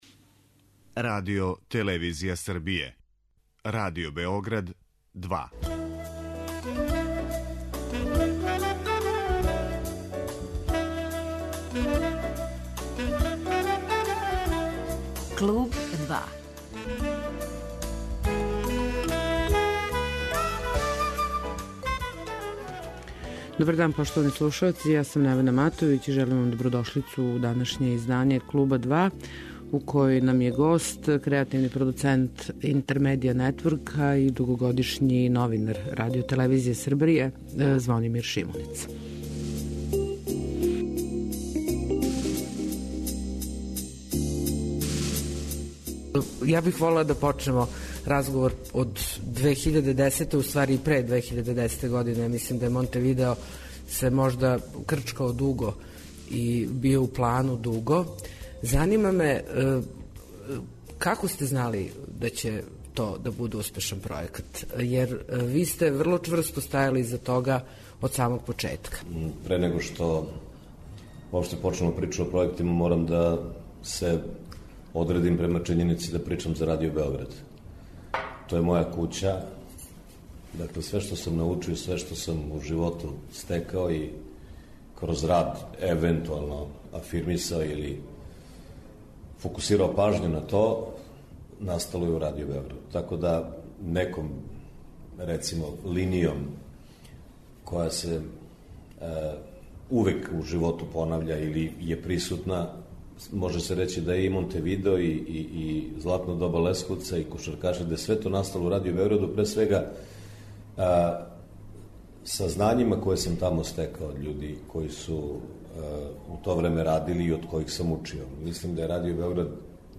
[ детаљније ] Све епизоде серијала Аудио подкаст Радио Београд 2 Сомерсет Мом: Паранг Мери Е. Брендон: Добра госпа Дукејн Андрија Мауровић: Тројица у мраку Жан Кокто: Антигона Срђан Вучинић: Драгутин Илић